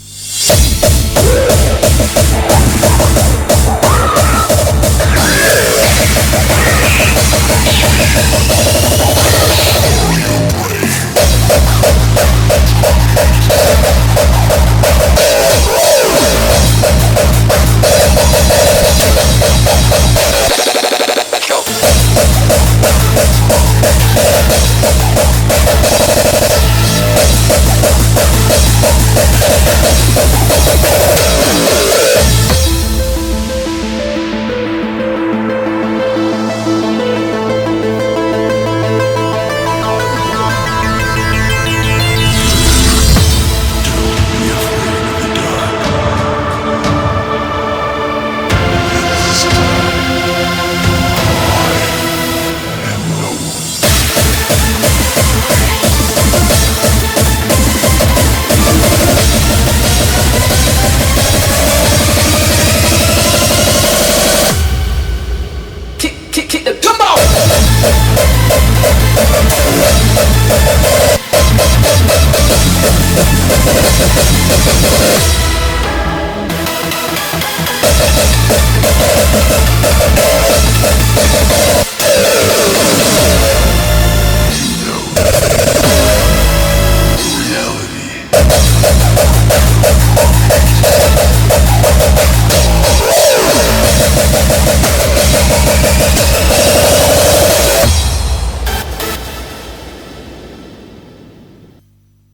BPM90-180
Audio QualityLine Out